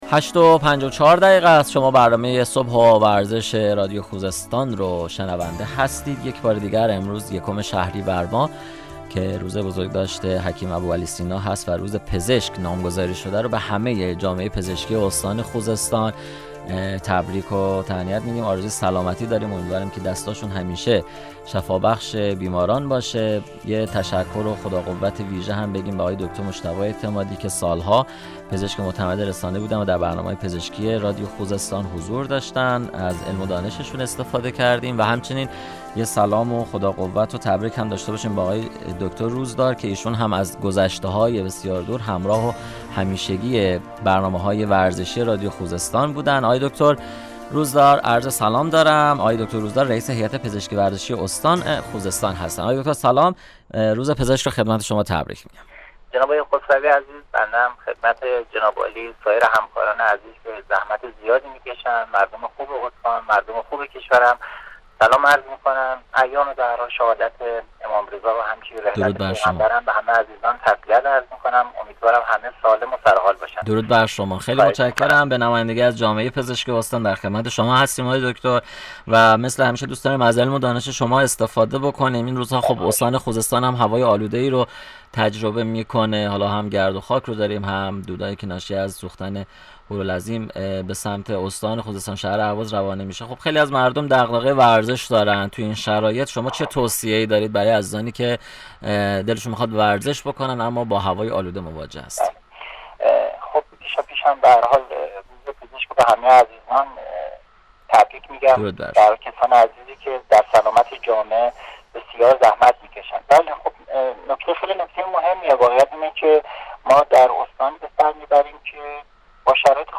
/ گفتگویی رادیویی /